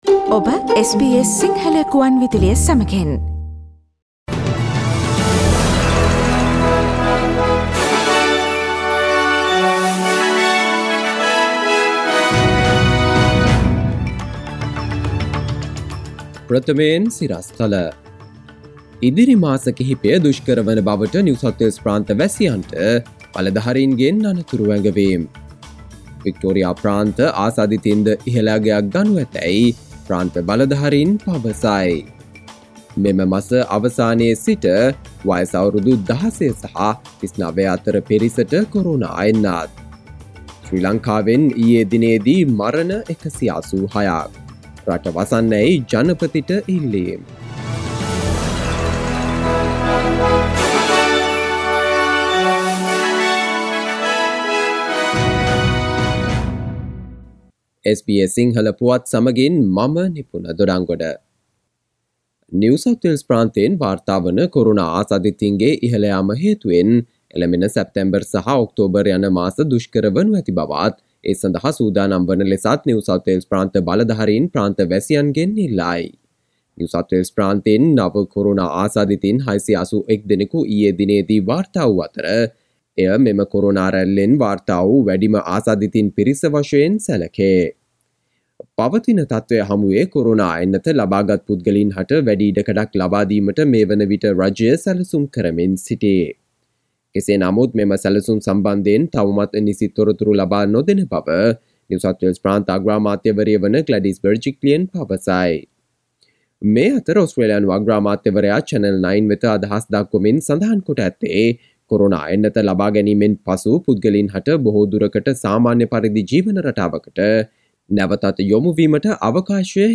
අගෝ 20දා SBS සිංහල ප්‍රවෘත්ති: ඉදිරි මාස කිහිපය දුෂ්කර වන බවට NSW ප්‍රාන්ත වැසියන්ට අනතුරු ඇඟවීම්